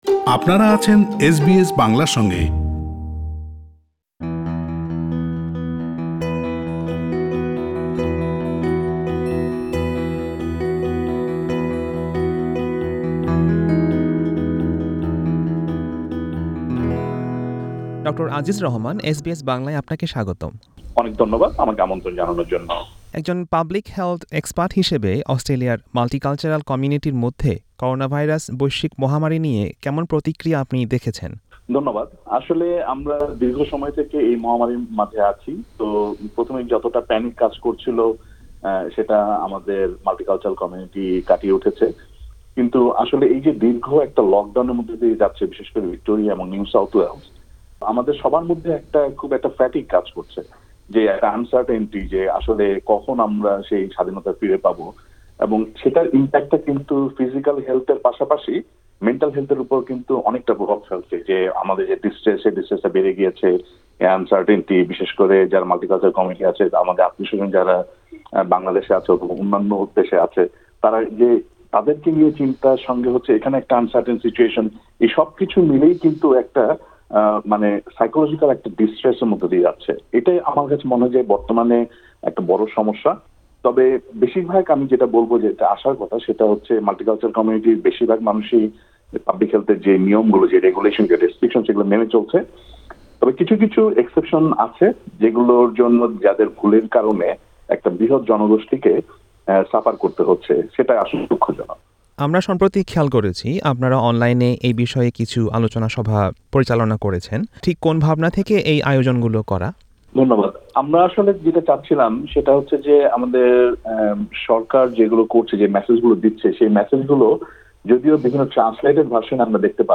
করোনাভাইরাস অতিমারি সহ জনস্বাস্থ্য বিষয়ে নিয়ে তার সাথে আলাপচারিতায় বিভিন্ন জনগুরুত্বপূর্ন আলাপ উঠে এসেছে।